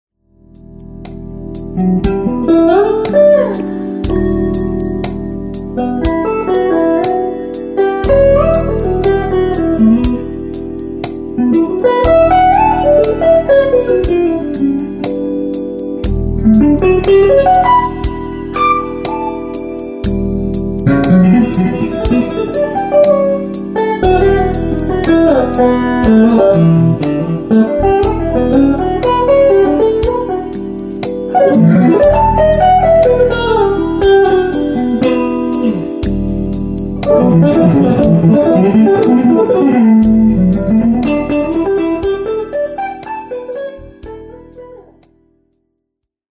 Per concludere vi presento una piccola improvvisazione [
ES.7] su un accordo di E-7; in questa improvvisazione, effettuata prevalentemente con le note della scala di D maggiore (suonando quindi un E dorico), utilizzo dei fraseggi che sfruttano moltissimo gli intervalli di quarta, questo per evidenziare il fatto che la bellezza ed efficacia di questo intervallo sugli accordi può essere parimenti trasposta nell'improvvisazione a note singole.